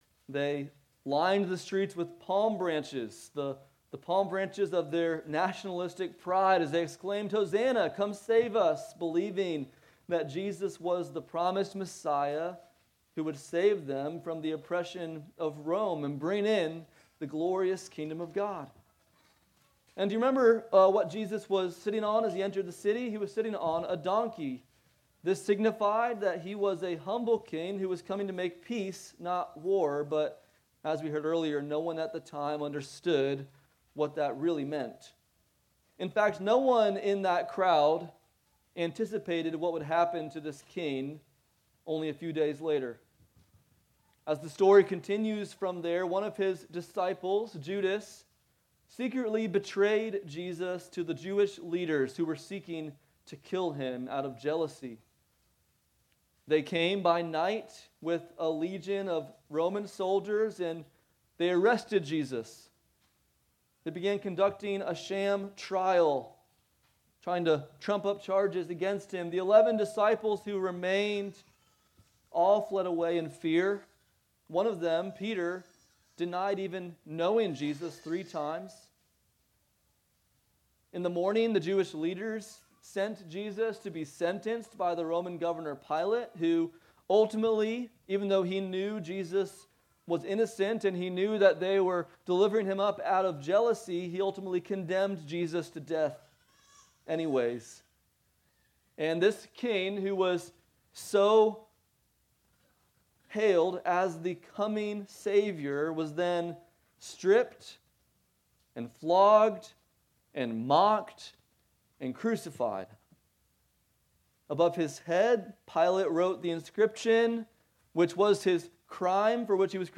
Christ Our King Passage: John 20:19-31 Service Type: Sunday Morning « Before You Share Your Faith